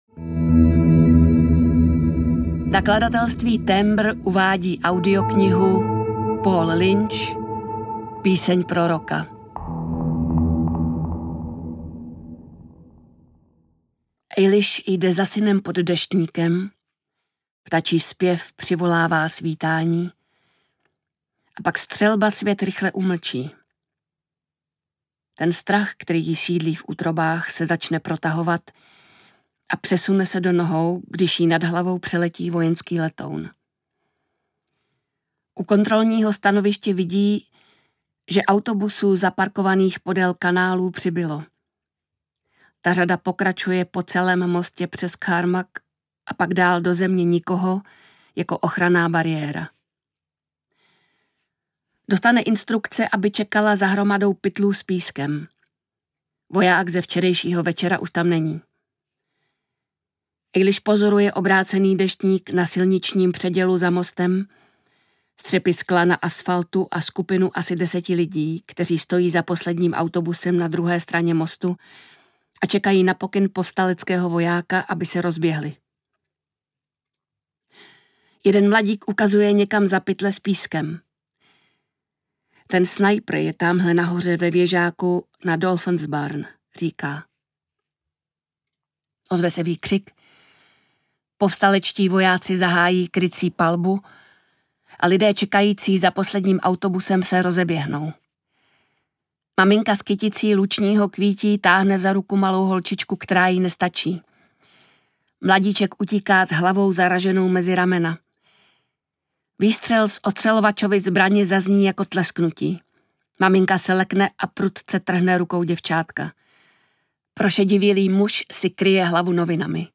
Audiokniha Píseň proroka, kterou napsal Paul Lynch.
Ukázka z knihy